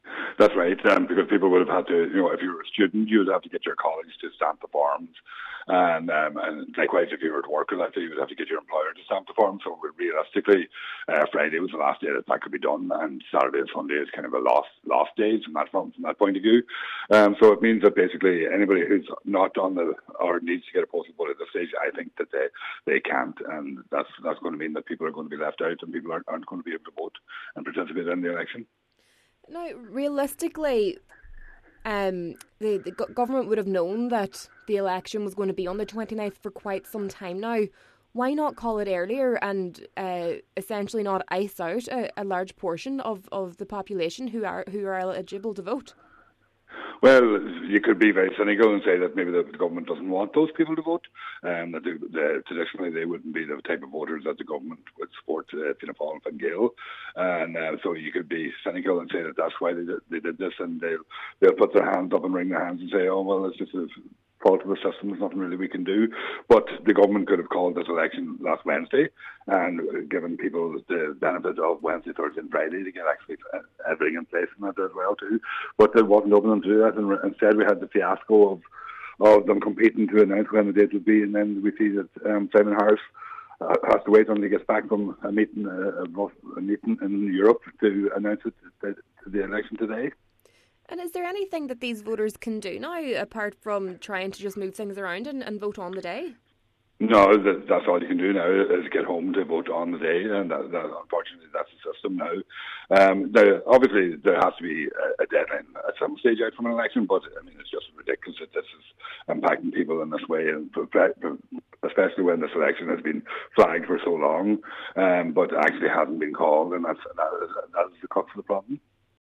Independent TD Thomas Pringle says it simply does not make sense: